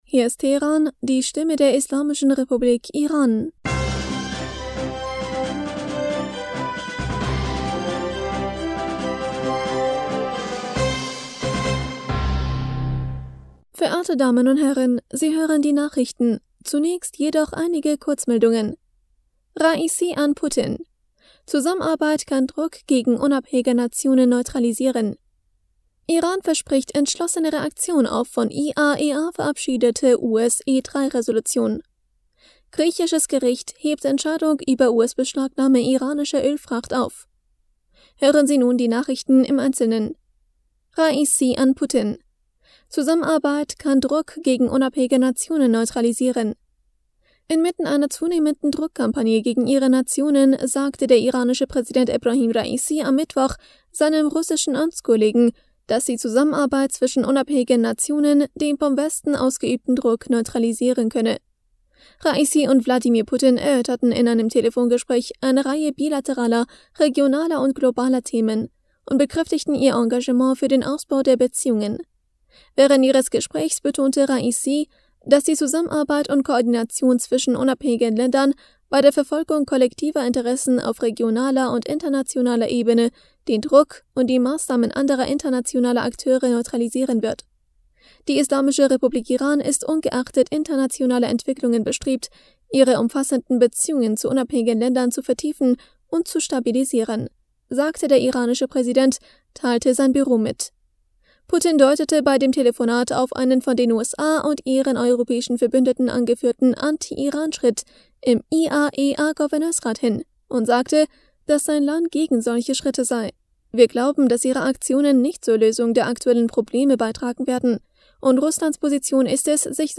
Nachrichten vom 9. Juni 2022